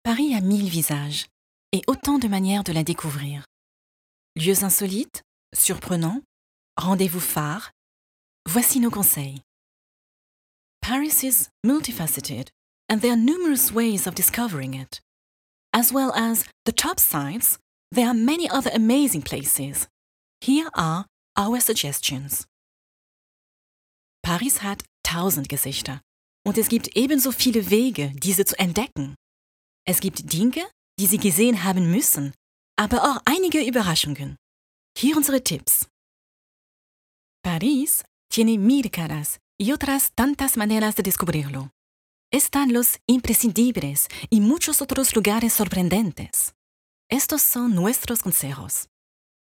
Livre audio